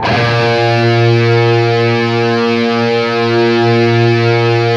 LEAD A#1 LP.wav